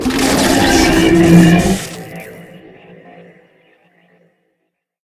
combat / enemy / droid / bighurt1.ogg
bighurt1.ogg